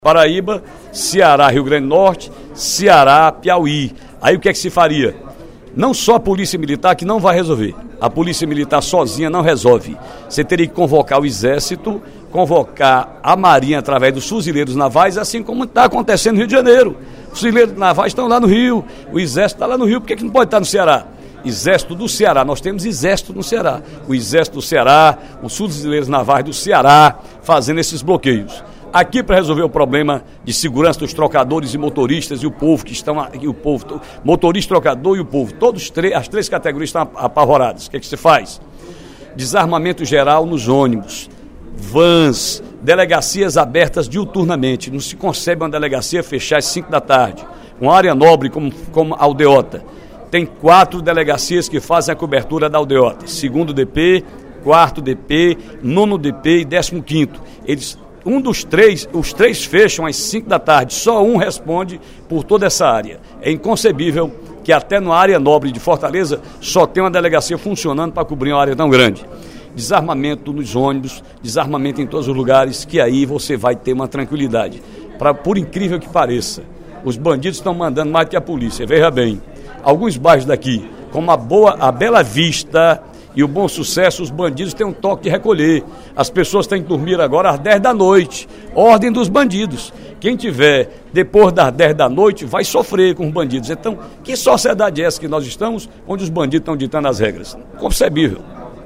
No primeiro expediente da sessão plenária desta quinta-feira (29/05), o deputado Ferreira Aragão (PDT) apresentou sugestões ao Governo do Estado para tentar resolver o alto índice de violência no Ceará.